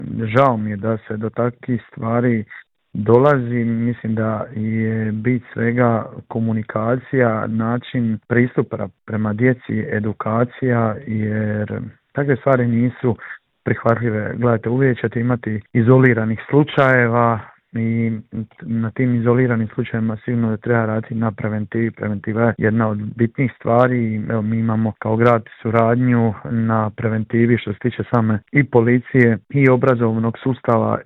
U Intervju Media servisa razgovarali smo s gradonačelnikom Splita Tomislavom Šutom koji nam je prokomentirao aktualnu situaciju i otkrio je li spreman za eventualne izvanredne izbore.